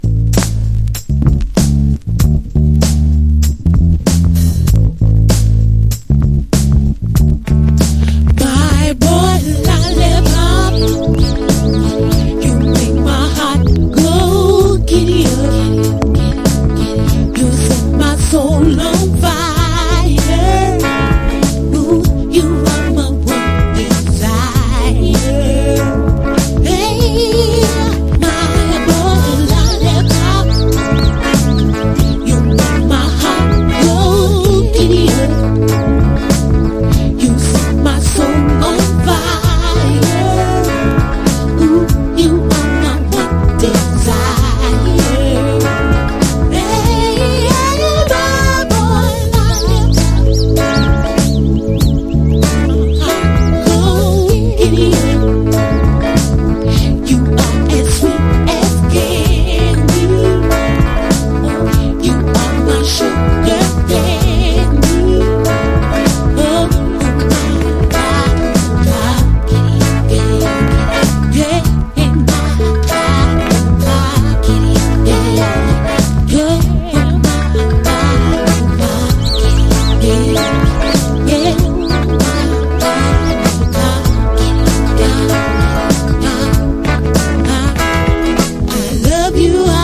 • REGGAE-SKA